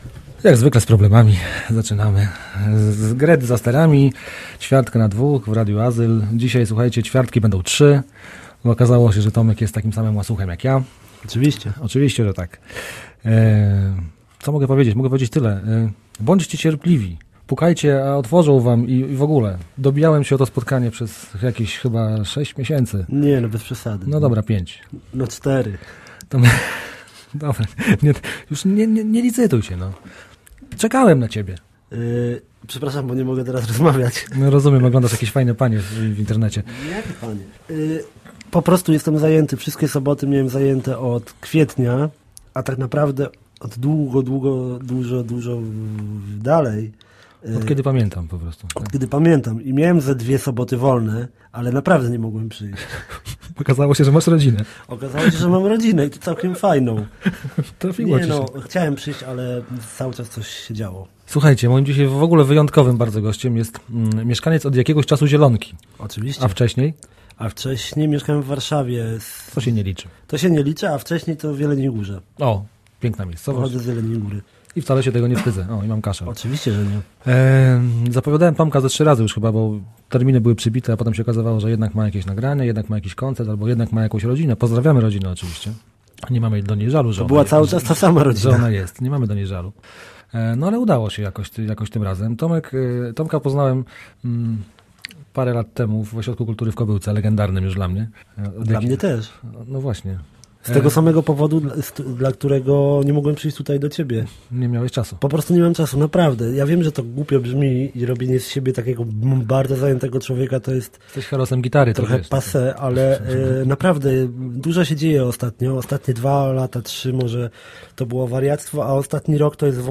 I na gitarce zagra, i pogada, i felieton napisze...
hobby, music, spotkania, takie tam..., wywiad